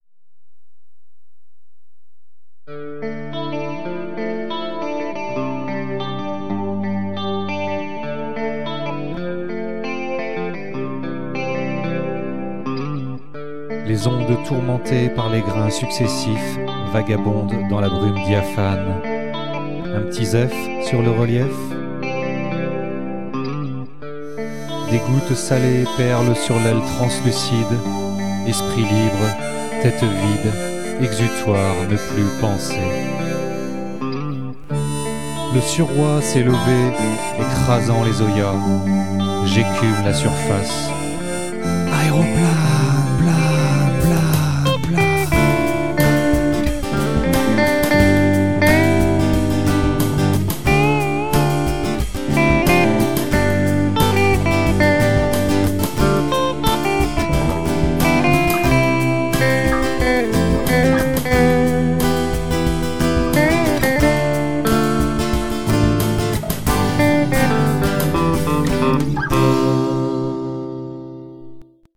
Un petit slam de début d'album ...